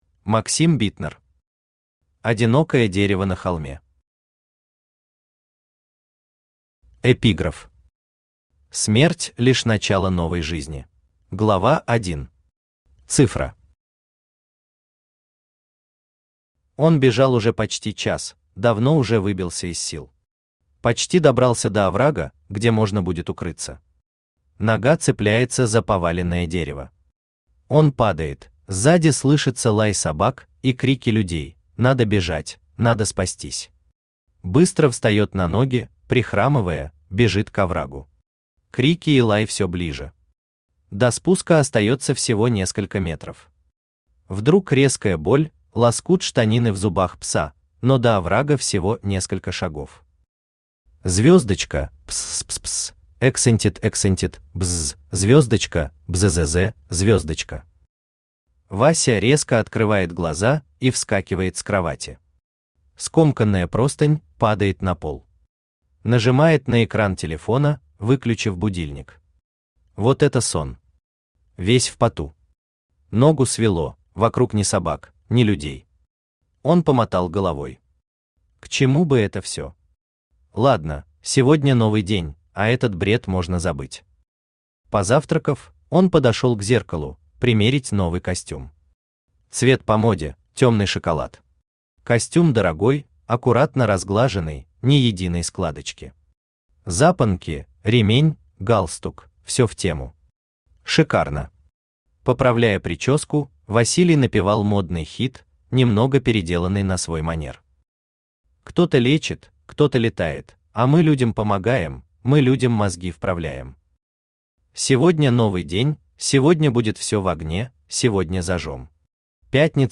Аудиокнига Одинокое дерево на холме | Библиотека аудиокниг
Aудиокнига Одинокое дерево на холме Автор Максим Владимирович Битнер Читает аудиокнигу Авточтец ЛитРес.